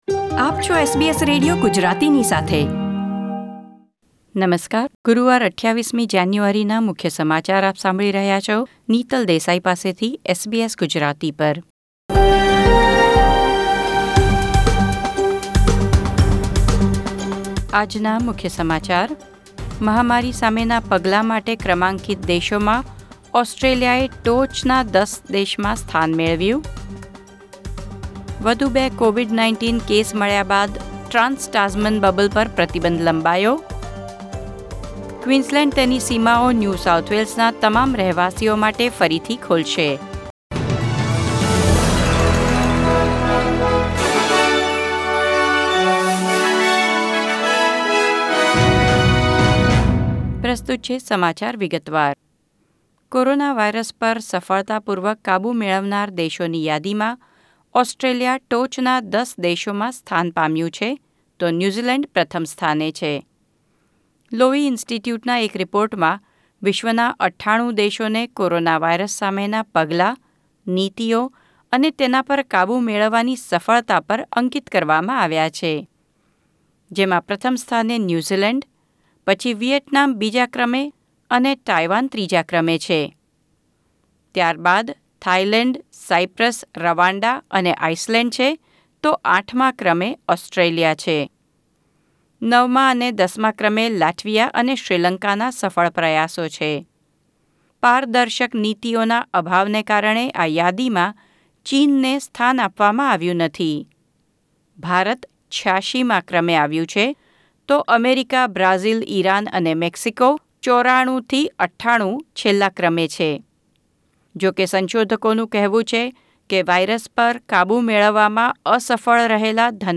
SBS Gujarati News Bulletin 28 January 2021